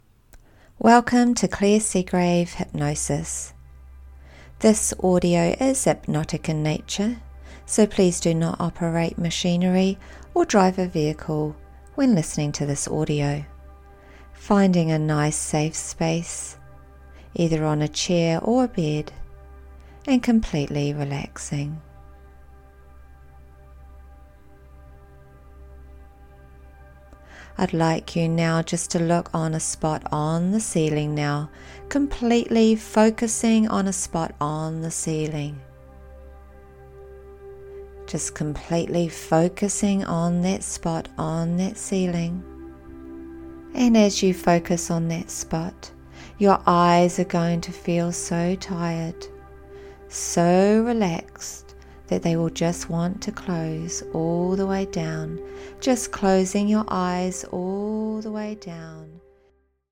Letting go of the past & step into the future hypnosis Audio
The audio has thirteen and half minutes in audio and has the 528 Hz music continue on until nineteen minutes.
Shortened-Advert-Computer-Clearing-with-528HZ-Prayer-For-Light.mp3